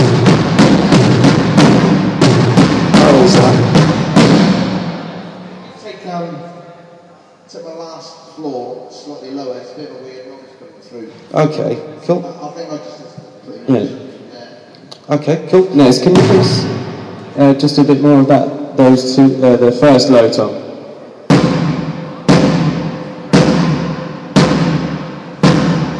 Soundchecking drums quite literally in a warehouse. ;)